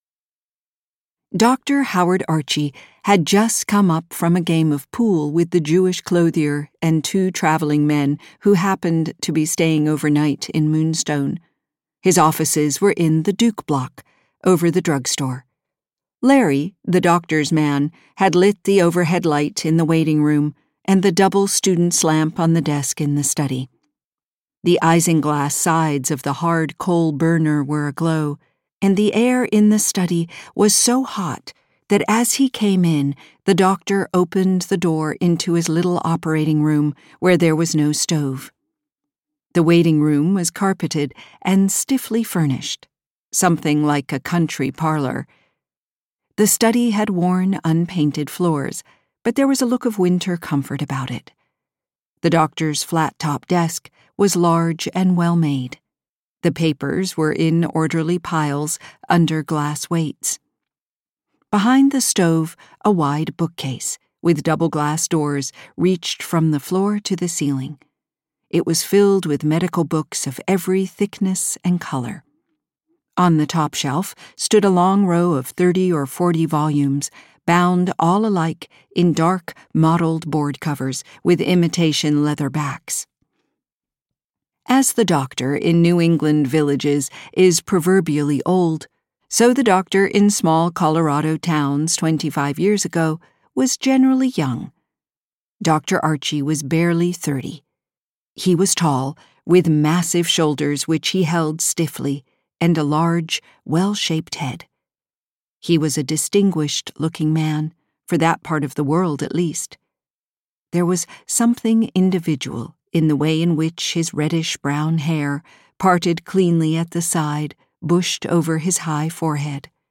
The Song of the Lark audiokniha
Ukázka z knihy